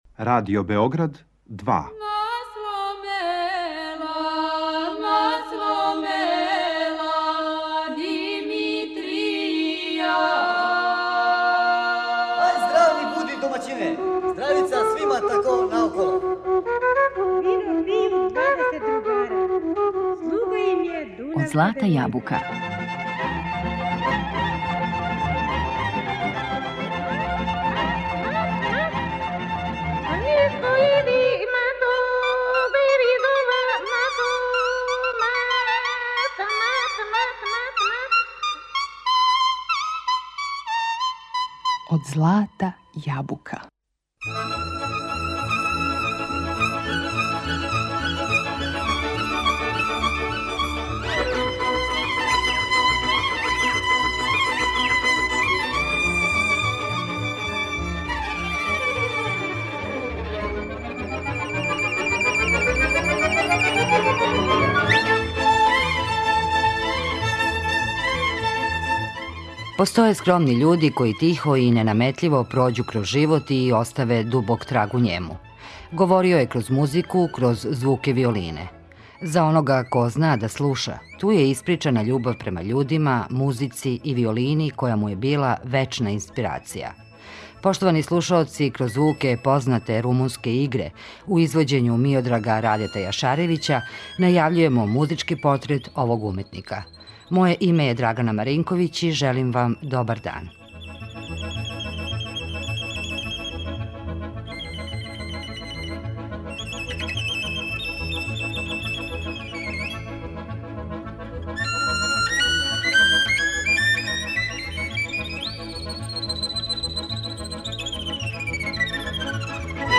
Његова виолина разликовала се од других по звуку, дивној мелодици и носталгичним тоновима. Зналачки је бирао песме и кола и непогрешиво извођаче.